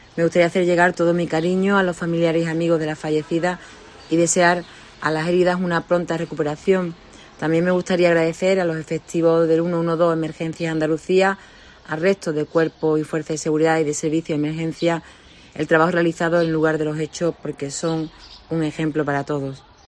Bella Verano, delegada de la Junta de Andalucía en Huelva